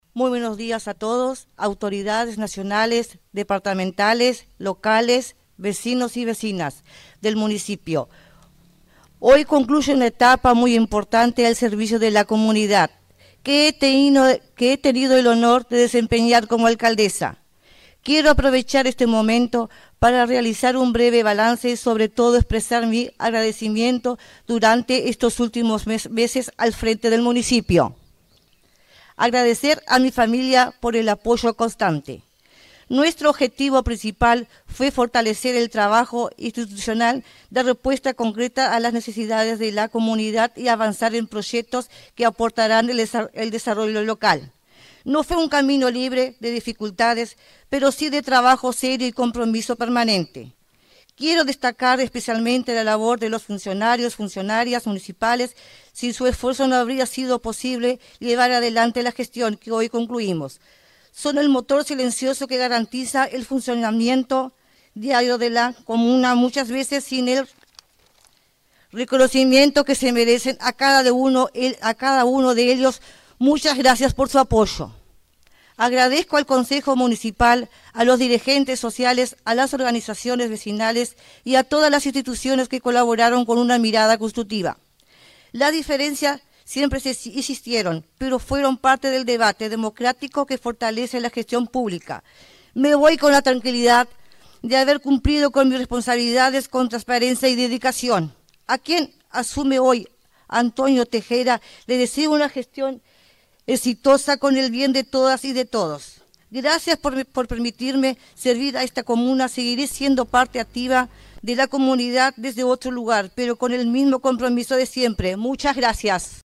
La alcaldesa saliente, Helen Mac Eachen, ofreció un emotivo discurso de despedida en el que destacó la importancia del trabajo institucional, el compromiso con la comunidad y el agradecimiento a su equipo.